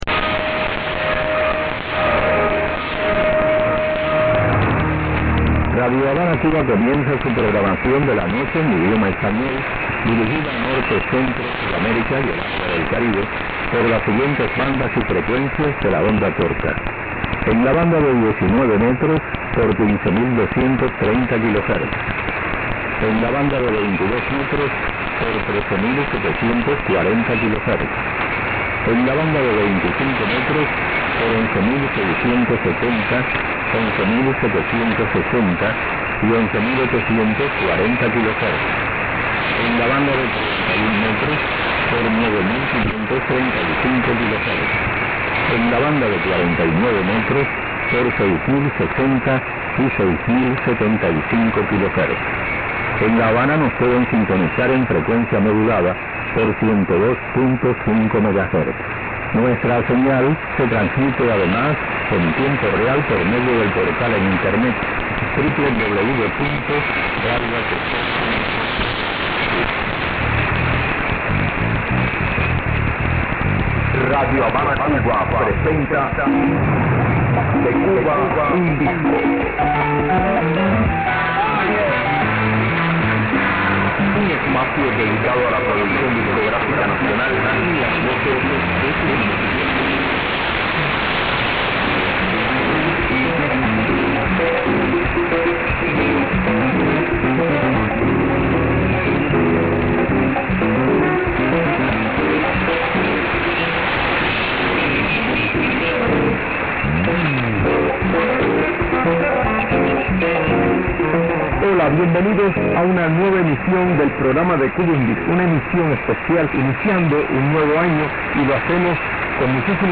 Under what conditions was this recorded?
The audio is parallel with 6060, 6075, and 9535 kHz.